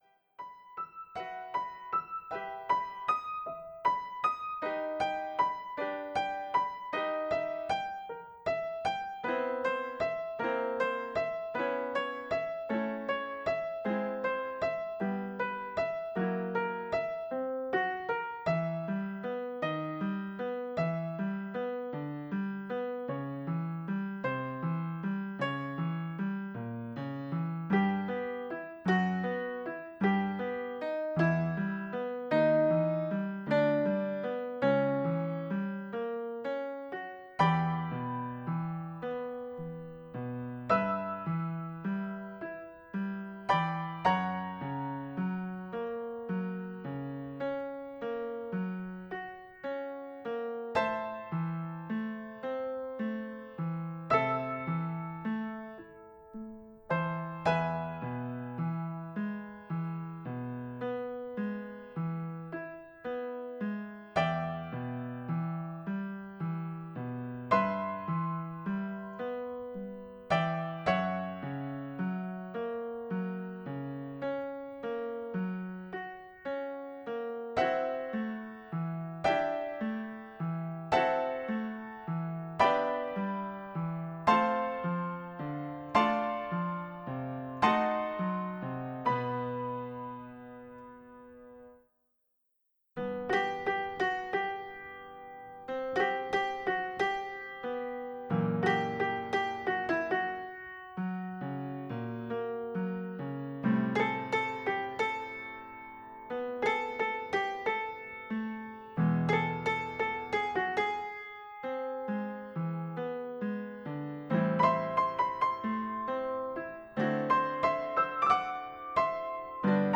the piece explores wide dynamics and nuanced phrasing